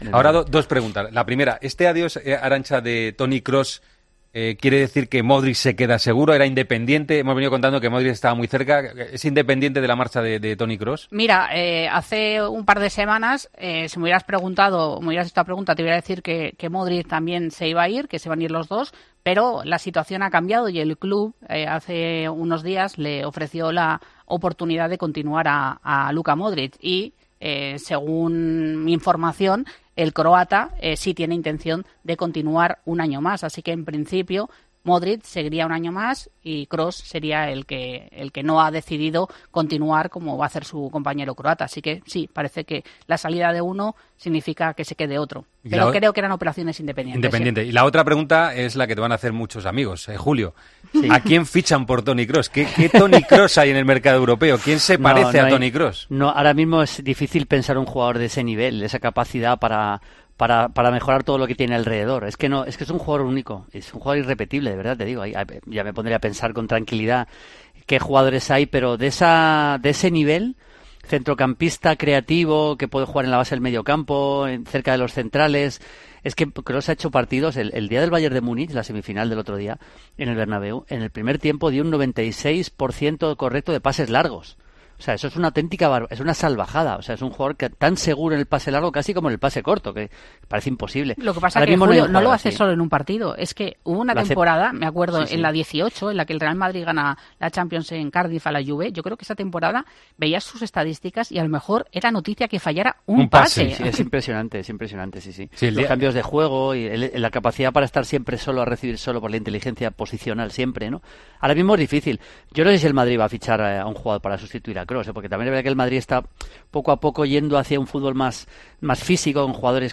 AUDIO - ENTREVISTA A LUKA MODRIC EN EL PARTIDAZO DE COPE.